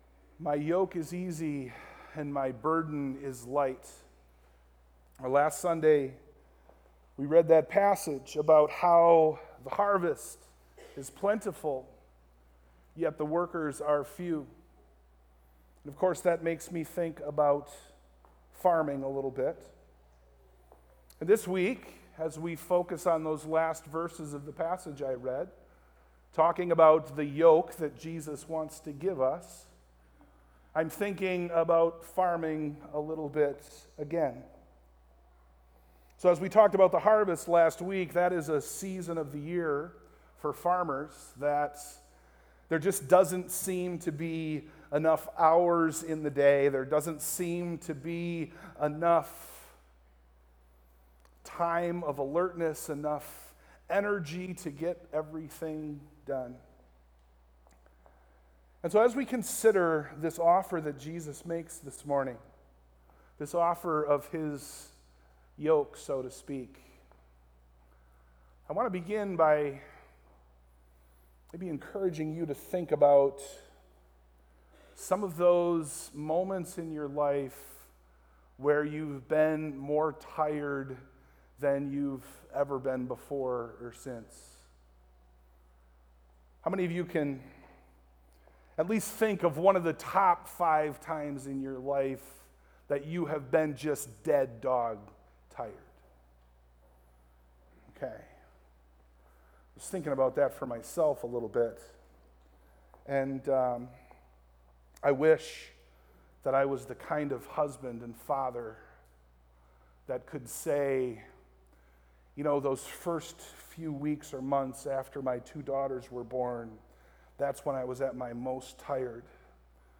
Passage: Matthew 11 Service Type: AM
Sermon+Audio+-+The+Easy+Yoke.mp3